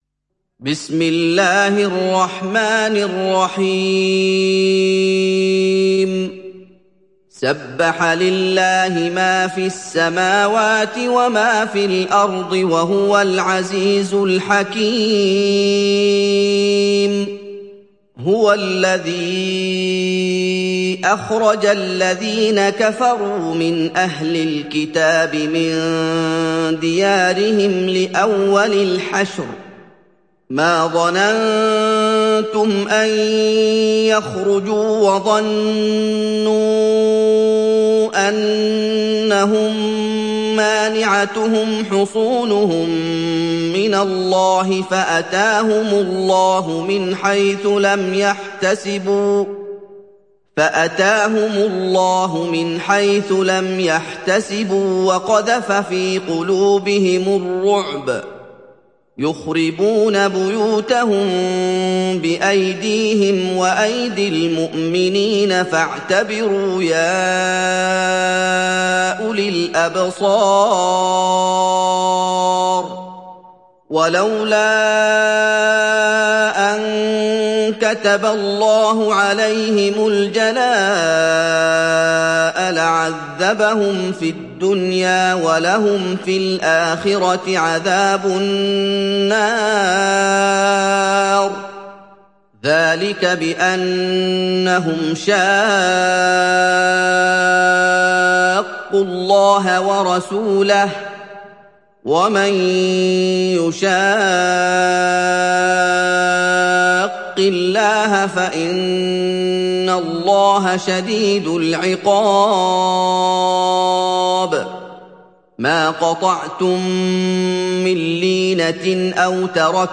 تحميل سورة الحشر mp3 بصوت محمد أيوب برواية حفص عن عاصم, تحميل استماع القرآن الكريم على الجوال mp3 كاملا بروابط مباشرة وسريعة